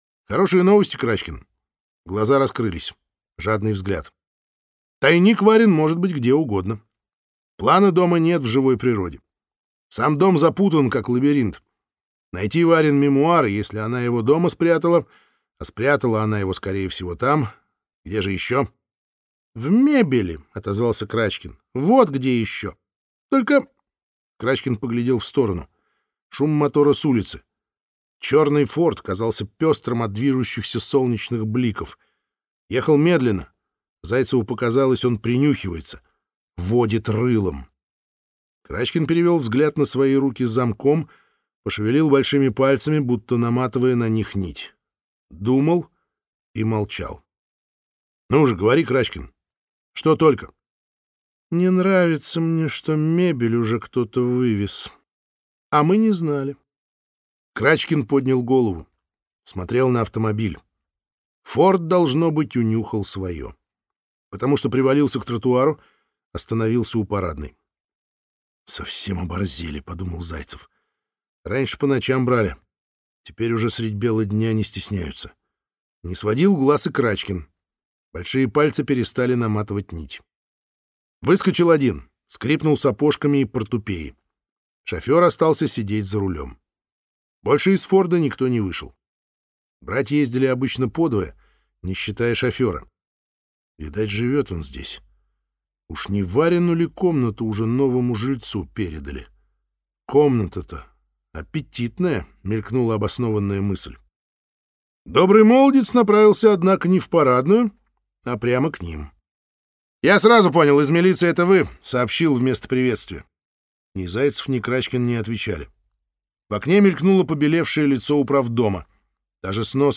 Невероятные приключения паровозиков. Сборник 4 (слушать аудиокнигу бесплатно) - автор Юлия Александровна Мельник